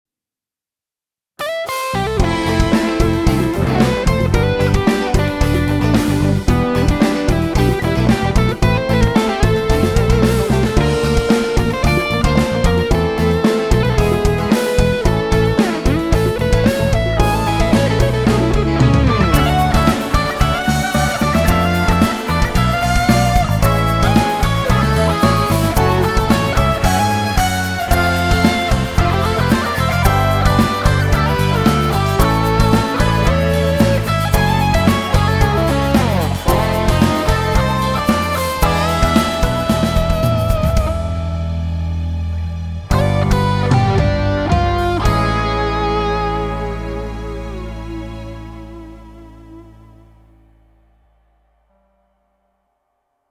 Soloing Over Famous Chords - Signals Music Studio.mp3